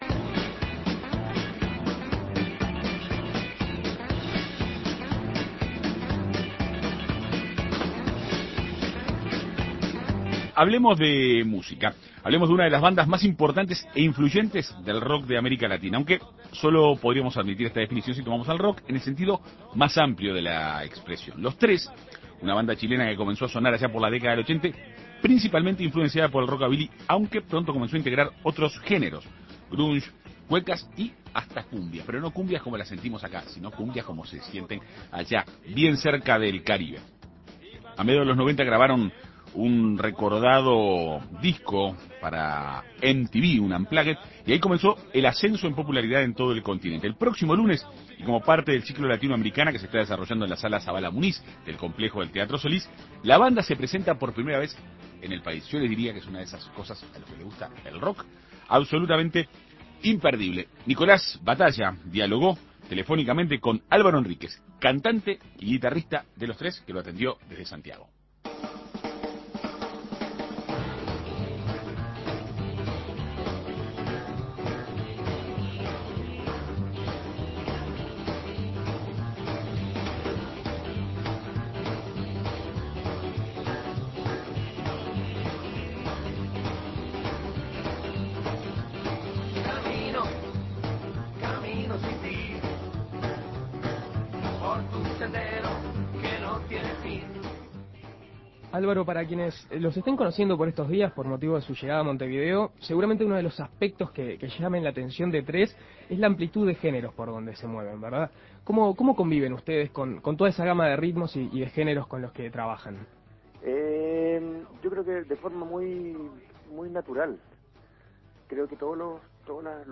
El próximo lunes, como parte del Ciclo Latinoamericana que se está desarrollando en la sala Zavala Muniz, la banda se presentará por primera vez en Uruguay. Álvaro Henriquez, cantante y guitarrista de Los Tres, dialogó con En Perspectiva Segunda Mañana.